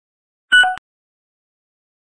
ding.mp3